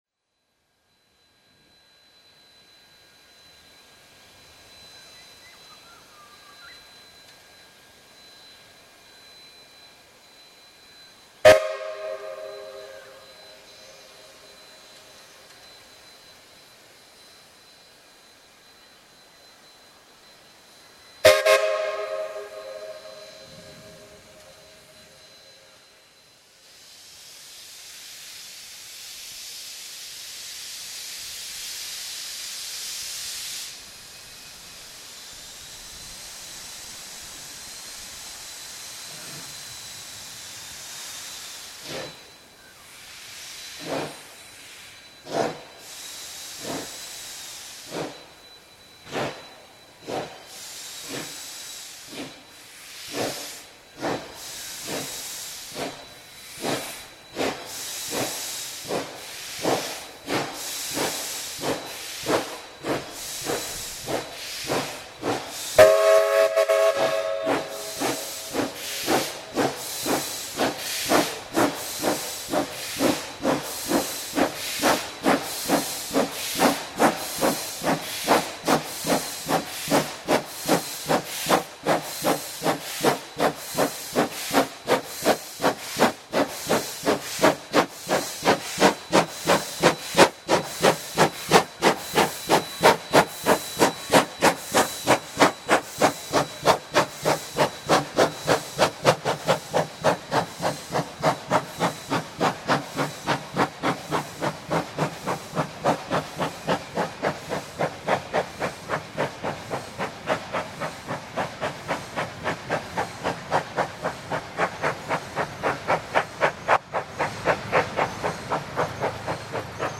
Have a listen to the train getting underway again (accompanied by rumbles of one of the wickedest series of electrical storms so far this year).